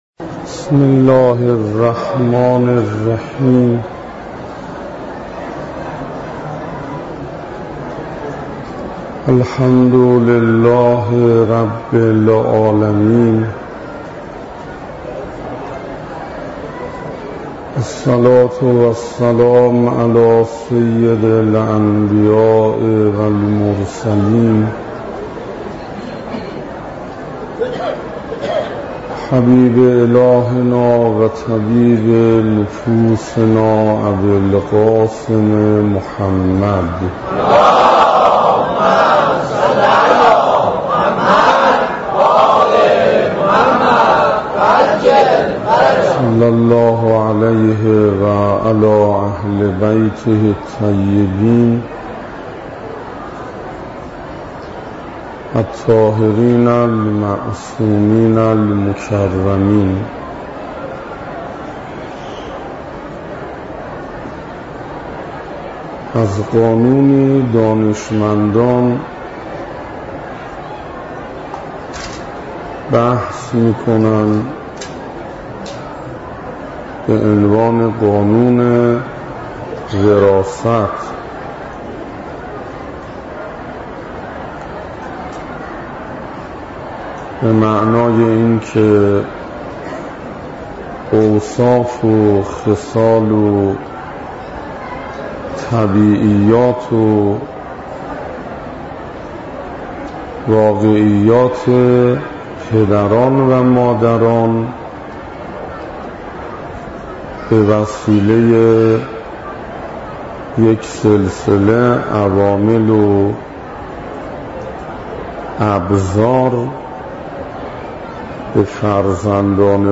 گلچین سخنرانی ها - گلچین سخنرانی ها: حضرت زینب سلام الله علیها - 0 -